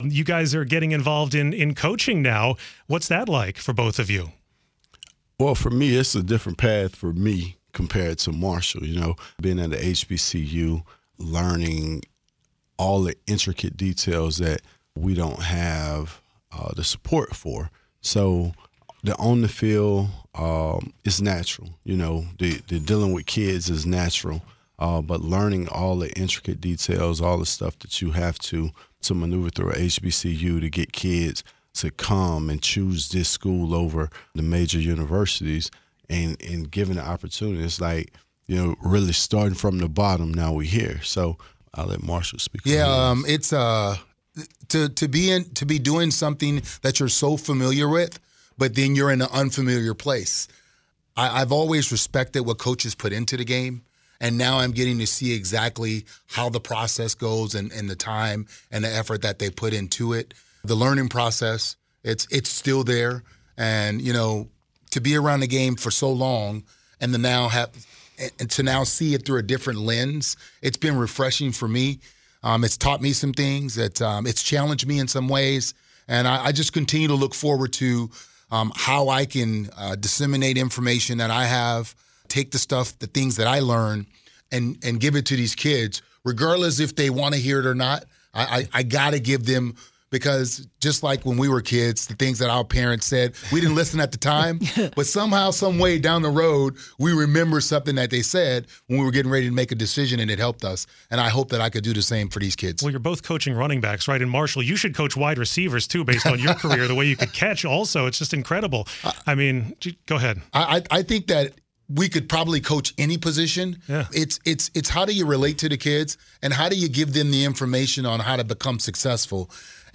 To do just that, former All Pro Washington running back Clinton Portis and Hall of Fame running back Marshall Faulk visited the WTOP studios Thursday.